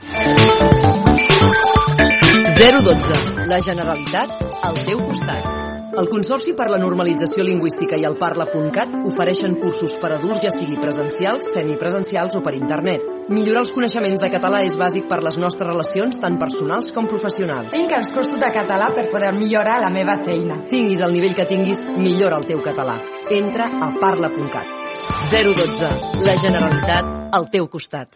Espai publicitari del telèfon O12 sobre els cursos en català de la Generalitat de Catalunya